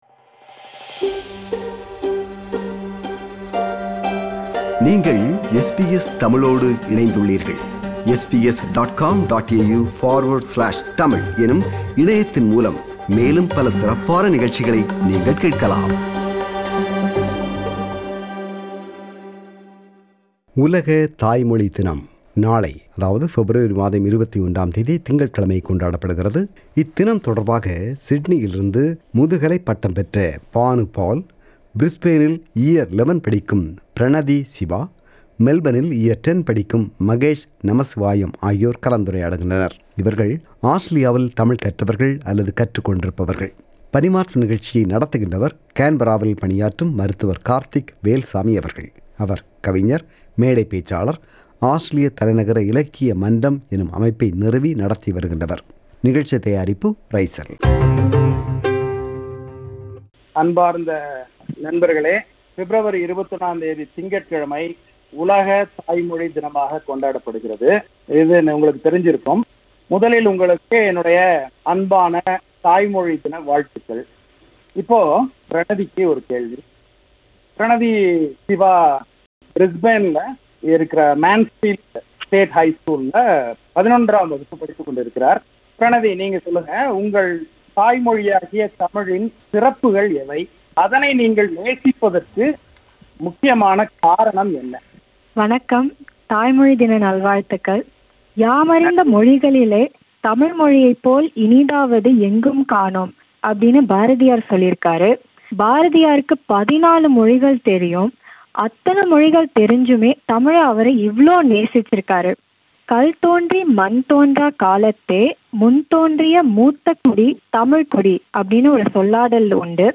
SBS Tamil conduct a panel discussion with three youth who are brought up in Australia and speak Tamil. They explain their experiences and challenges in maintaining the language skills.